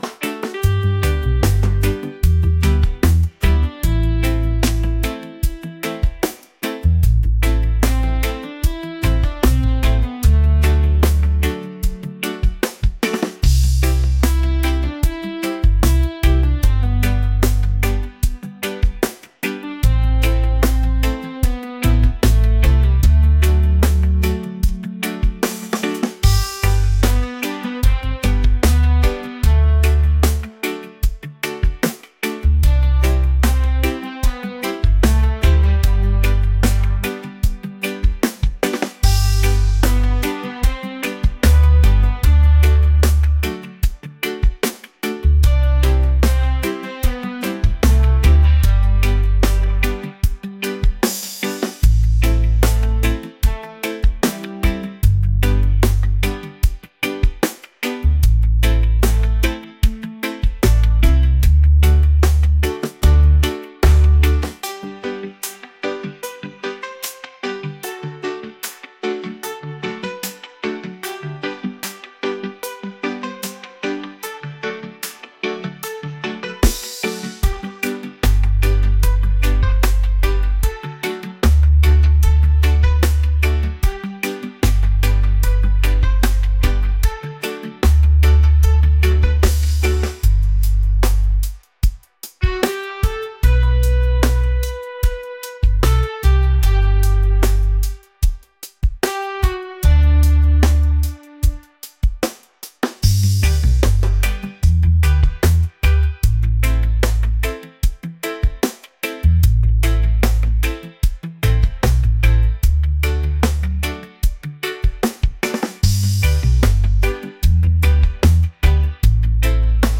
mellow | laid-back | reggae